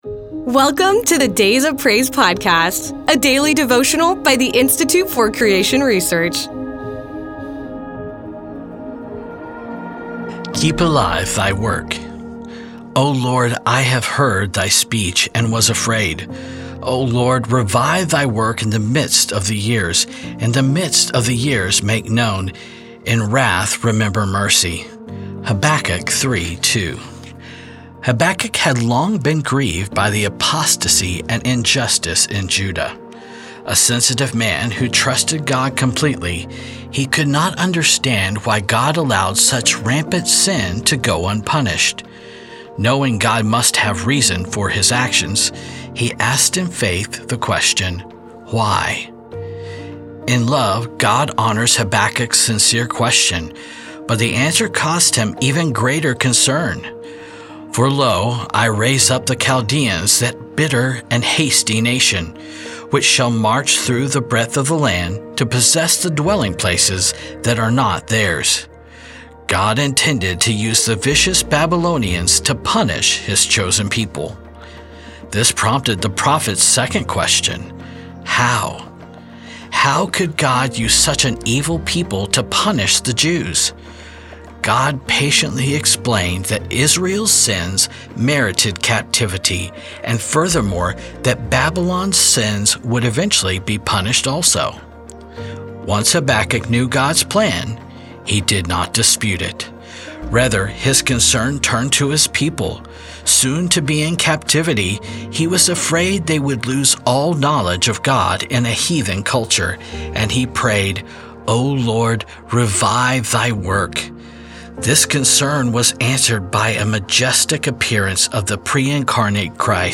Creation Biblical Truth Faith God Sovereignty Devotional